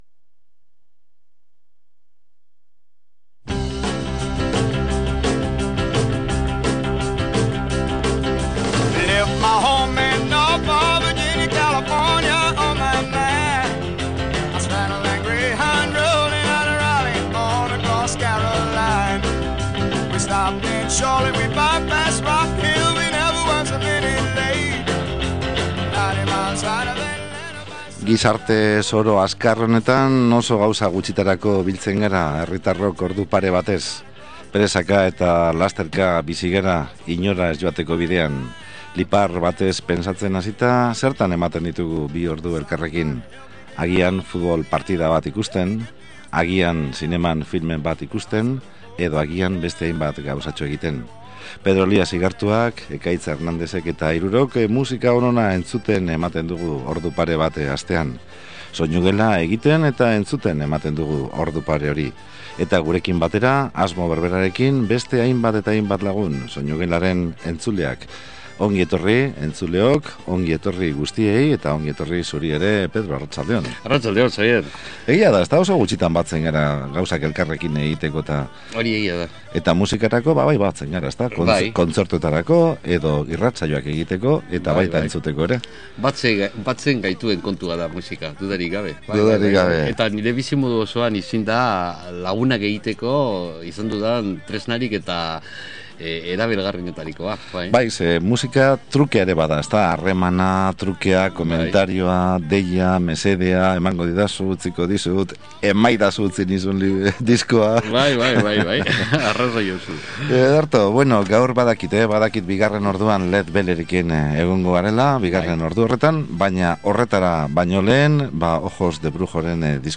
Geroztik, Ingalaterratik country alternatiboa egiten duen The Broken Family Band taldea ezagutu dugu.